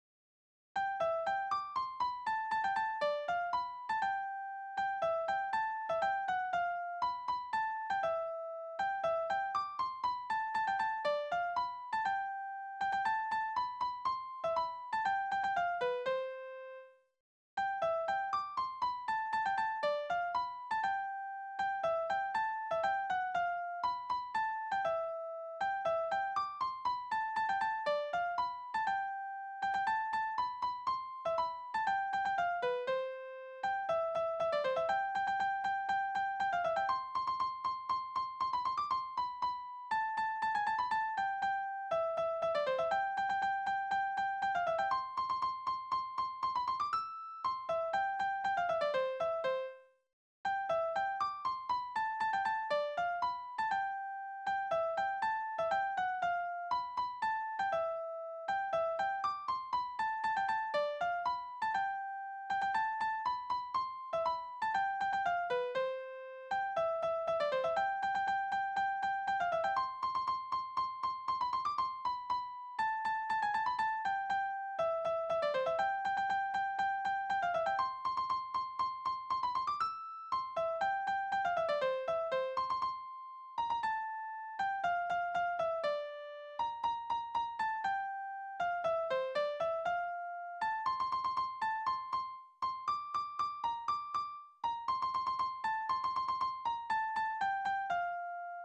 Tonart: C-Dur, F-Dur Taktart: 2/4
es handelt sich um ein Instrumentalstück